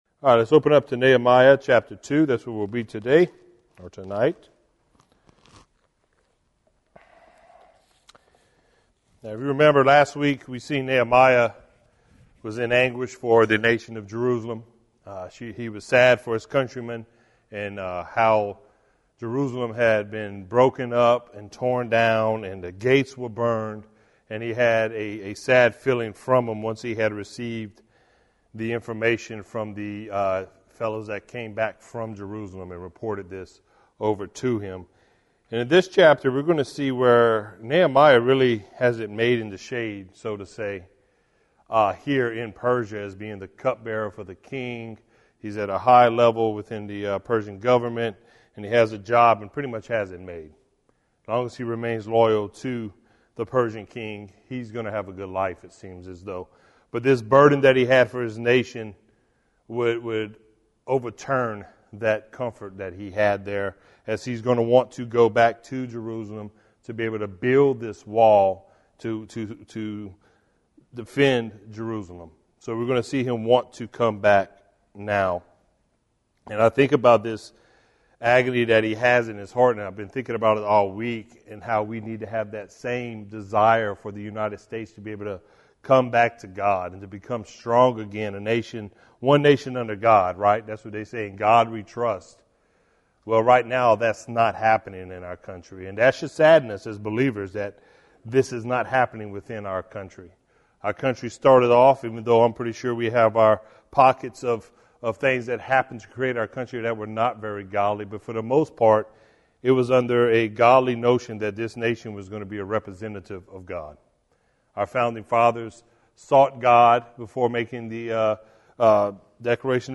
verse by verse study